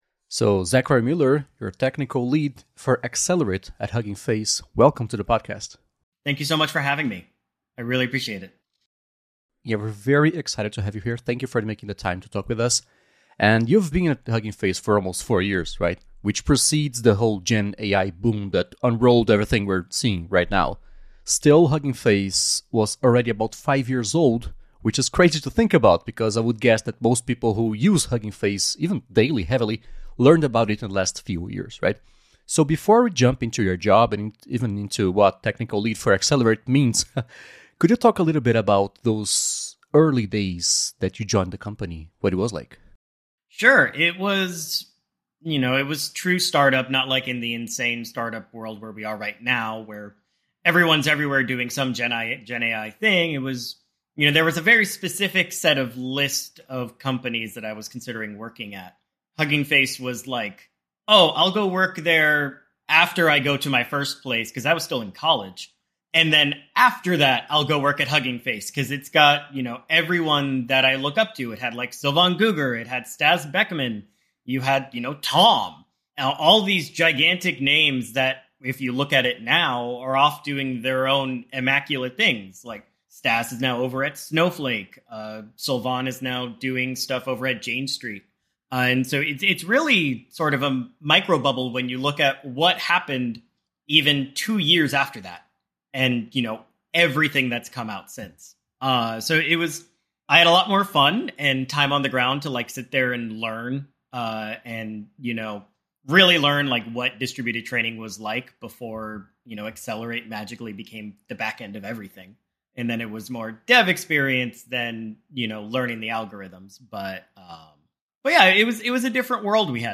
Entrevista original